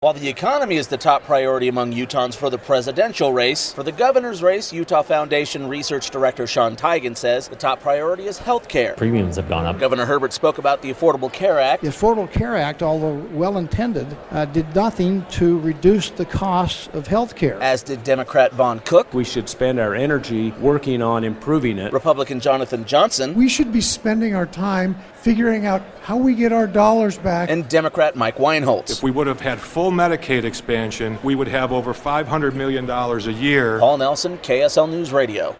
Four of Utah's gubernatorial candidates speak about top issues
Four candidates from both major parties spoke about these issues at the 2016 Priorities Project Luncheon.